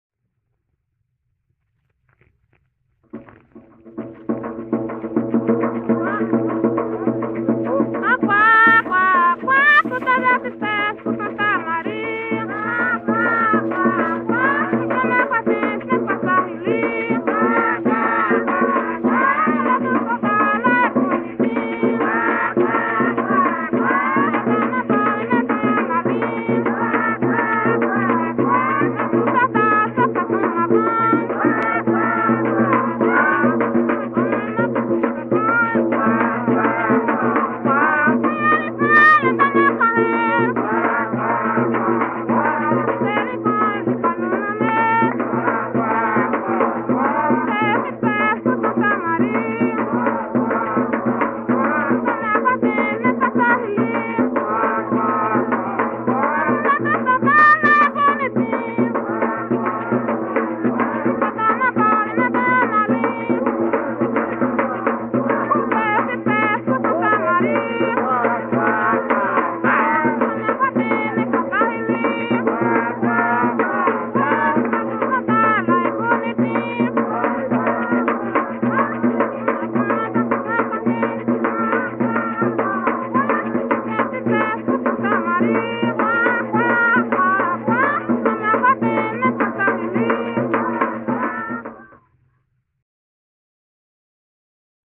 Coco solto -""Qua, qua, qua...""